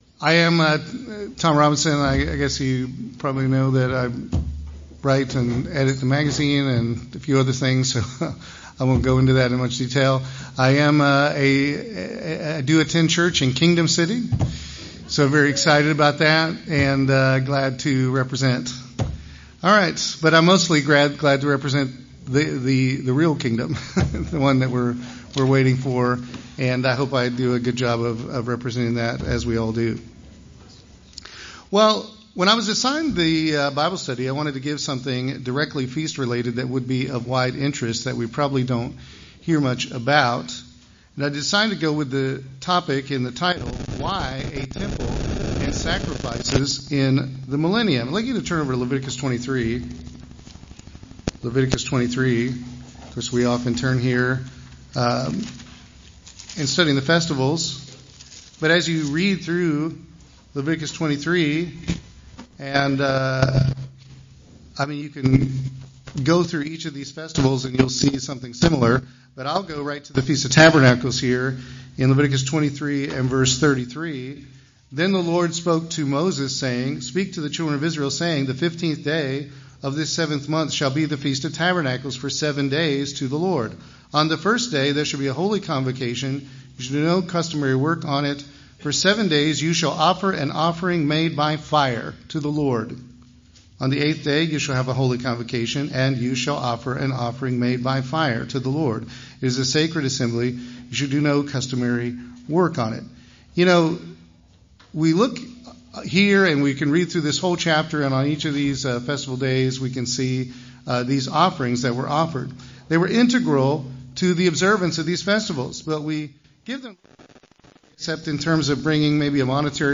This Bible study will discuss the fact that a functional temple system with sacrifices will exist in the Millennium and explore the reasons for that. I will use the last chapters of Ezekiel to present a number of details about this and compare with the need for the original tabernacle and Temple.